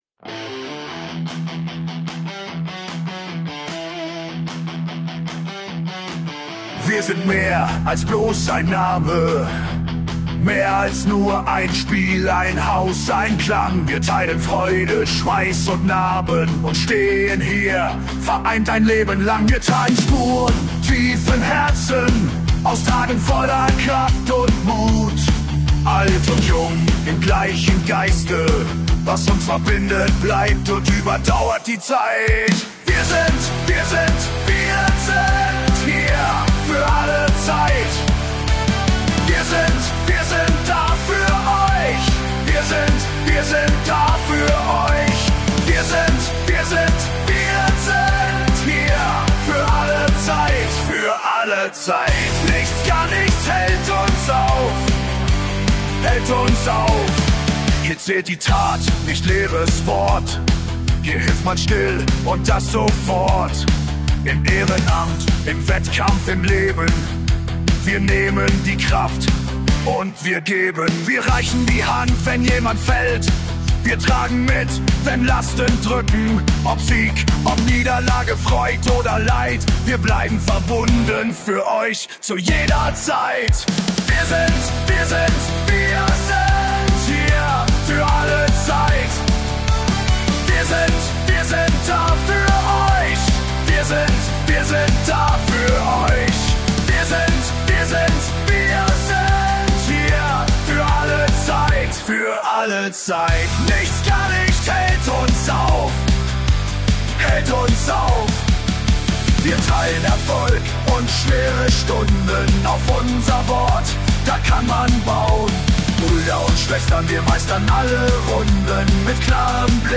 verbinden Rock, Metal, Pop und Country